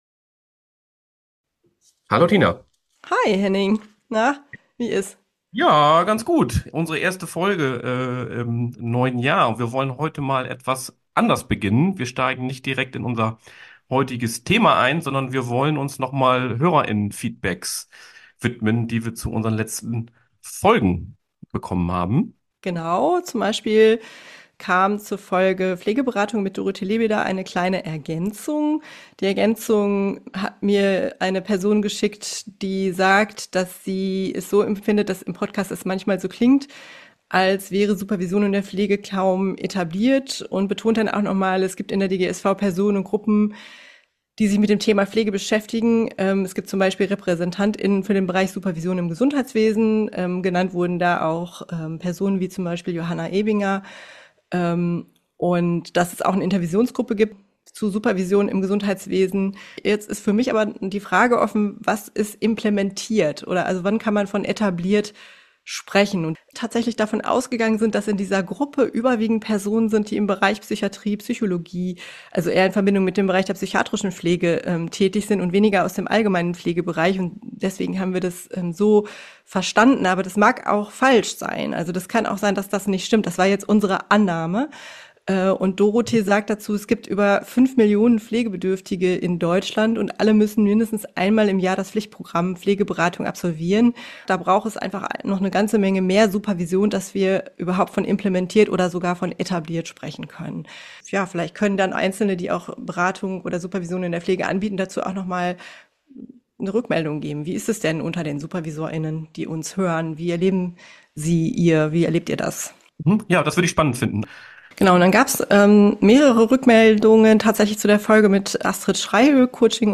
In der Folge sprechen wir mit der Sexualpädagogin und Supervisorin